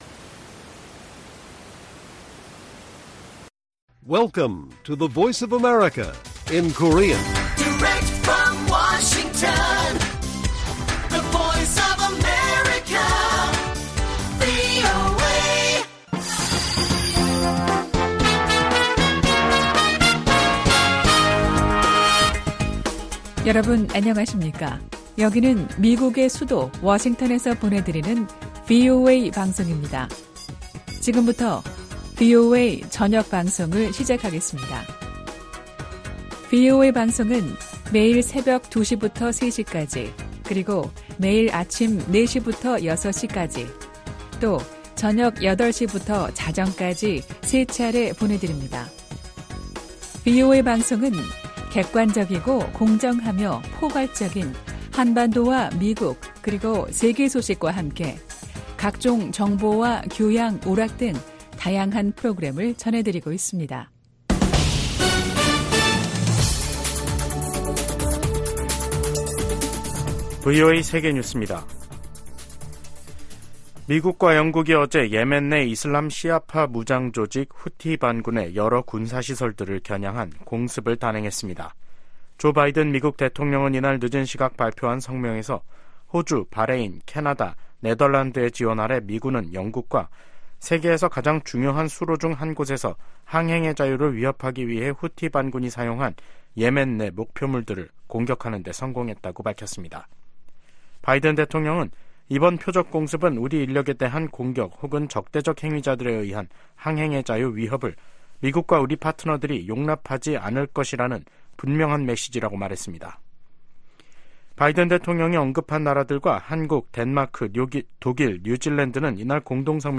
VOA 한국어 간판 뉴스 프로그램 '뉴스 투데이', 2024년 1월 12일 1부 방송입니다. 미국이 북한 탄도미사일의 러시아 이전과 시험에 관여한 러시아 기관과 개인에 제재를 가했습니다. 미 국무부는 북한제 미사일 사용 증거가 없다는 러시아 주장을 일축했습니다. 국제 인권단체 휴먼라이츠워치는 '2024 세계 보고서'에서 북한 정부가 지난해에도 코로나 방역을 핑계로 계속 주민들의 기본권을 침해했다고 비판했습니다.